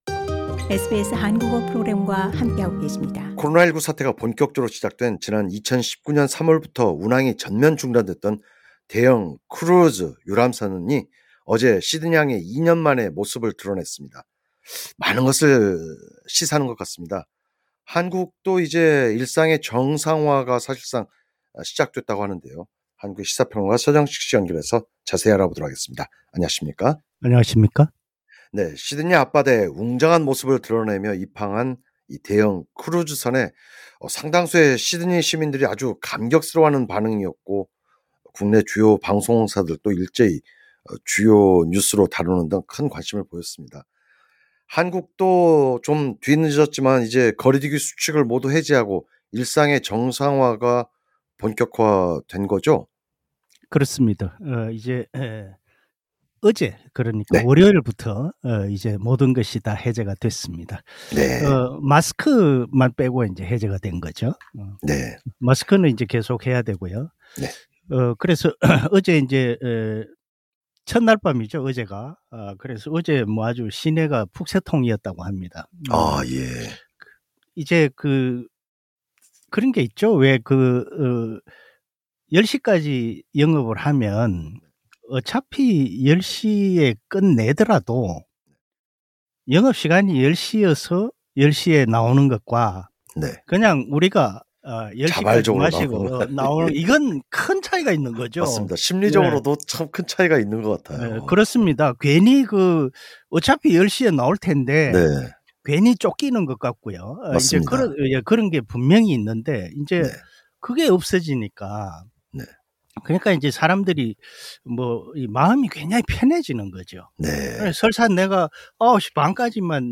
해설: 시사 평론가